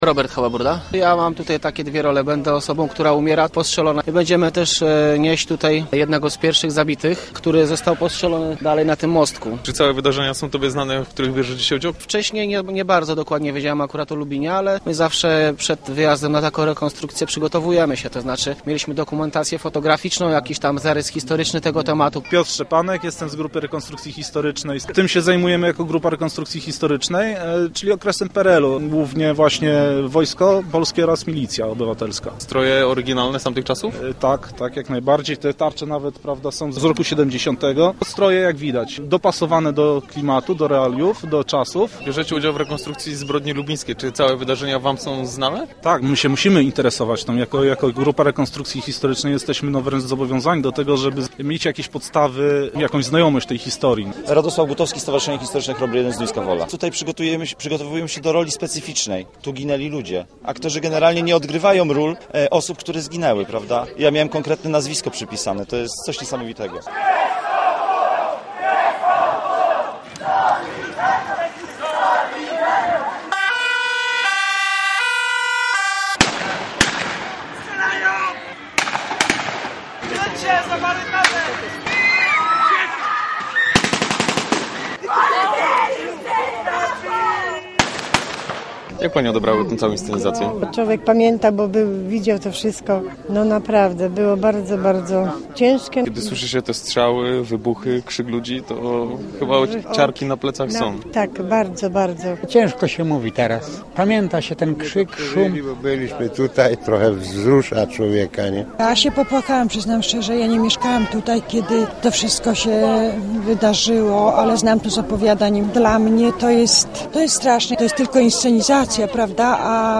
Krzyki, strzały, syreny i ogólne uczucie strachu – tak wyglądała inscenizacja, którą zorganizowano w 30 rocznicę Zbrodni Lubińskiej. Akcja rozgrywała się na lubińskich błoniach, czyli w miejscu, gdzie faktycznie miały miejsce dramatyczne wydarzenia.